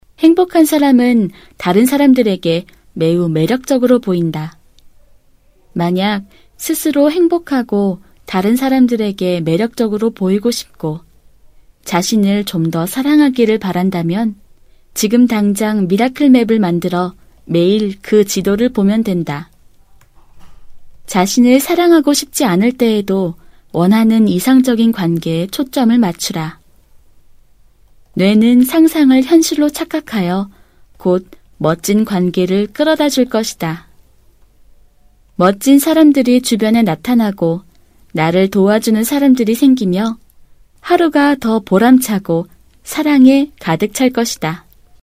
女韩02 韩语 讲述产品讲解甜美绘本故事课件 娓娓道来|积极向上|时尚活力|亲切甜美|素人